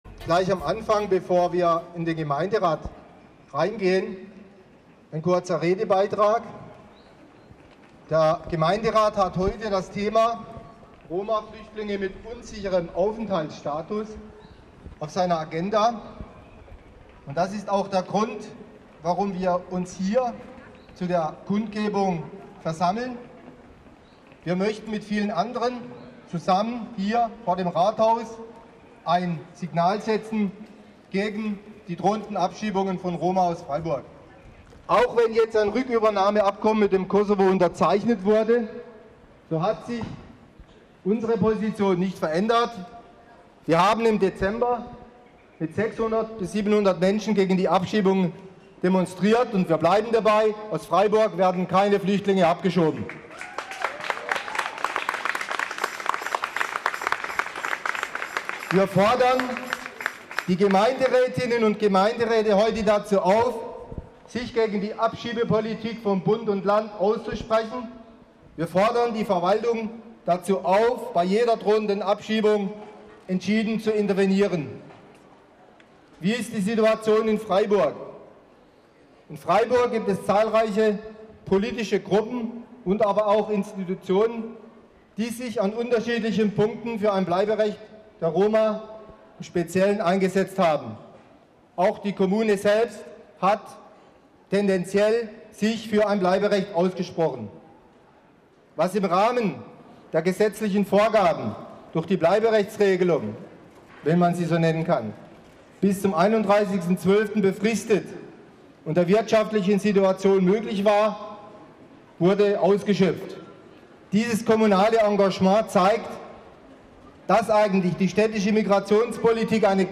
Bleiberecht für Roma Kundgebung am 27.4.2010